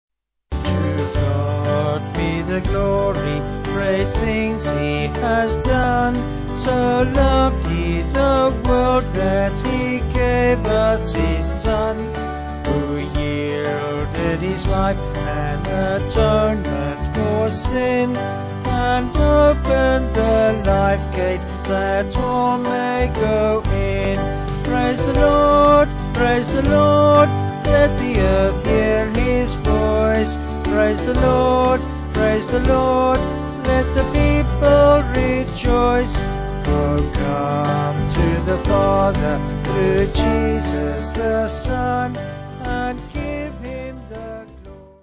Hymn books
Vocals and Band